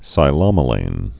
(sī-lŏmə-lān)